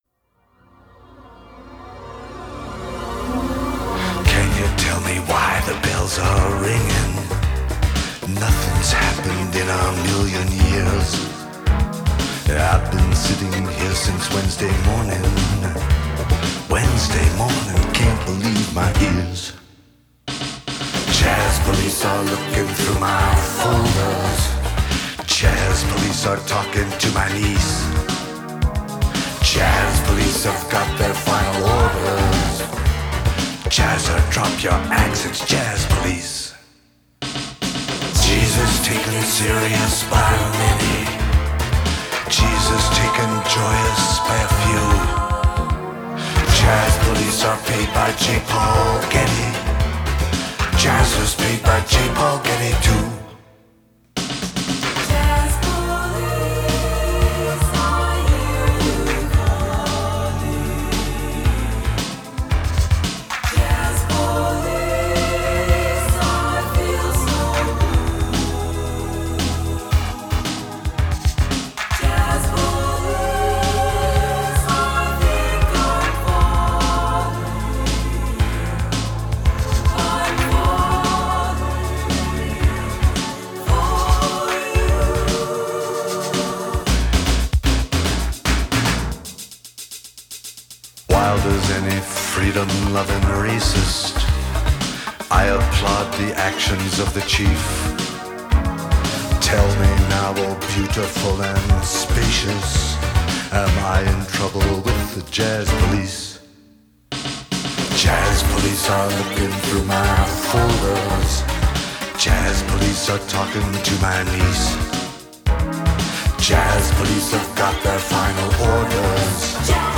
Жанр: Electronic, Rock, World, Folk, Synthpop